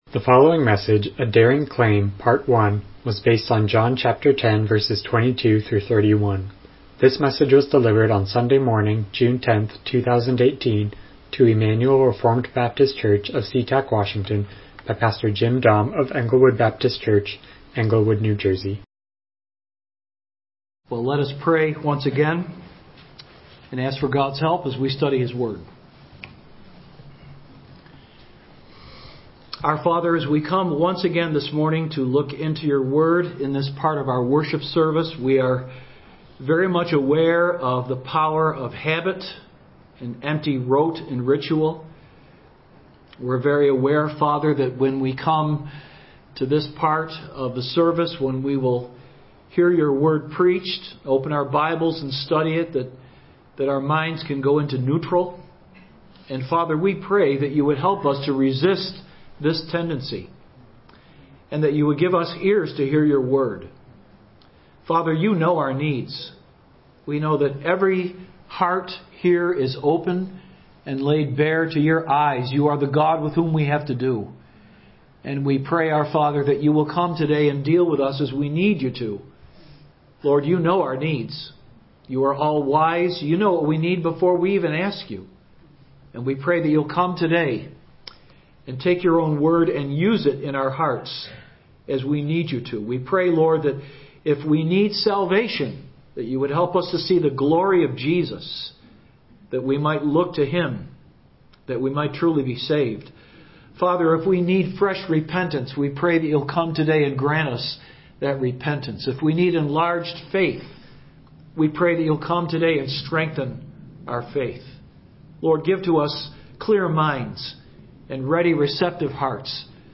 John 10:22-31 Service Type: Morning Worship « The Gift of the Holy Spirit